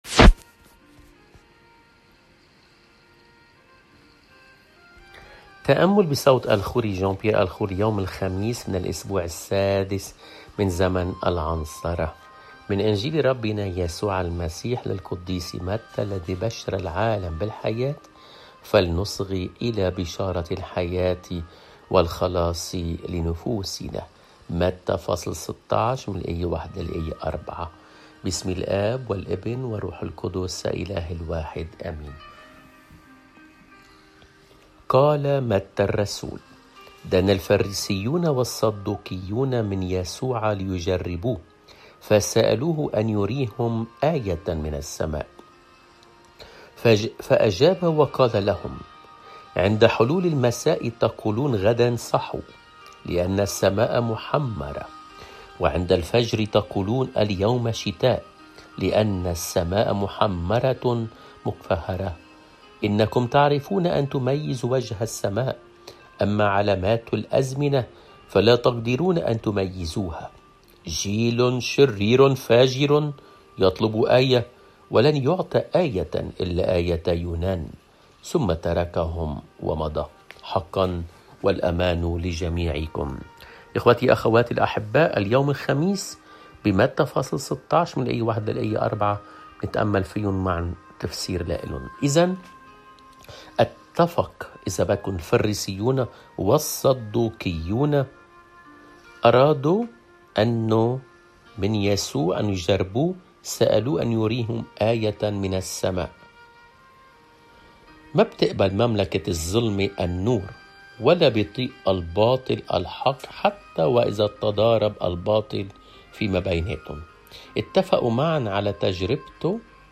الإنجيل